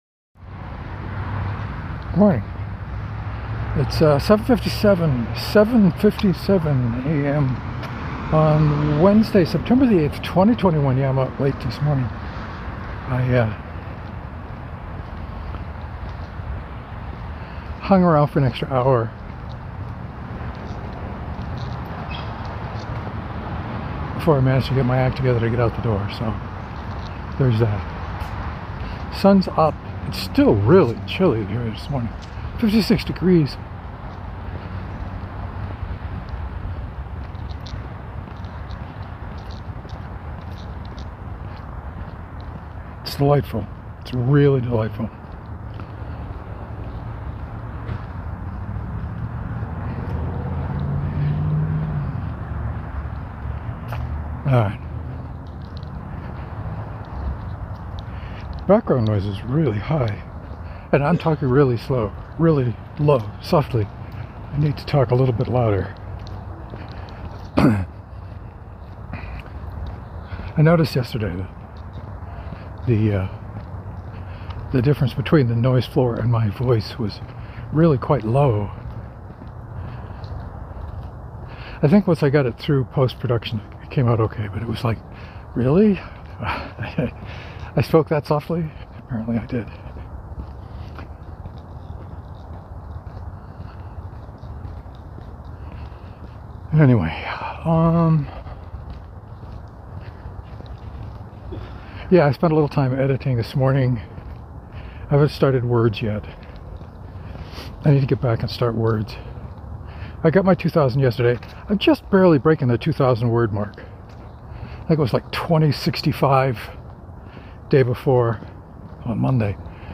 Note: I edited the file to lower the volume on a couple of really loud pickup trucks.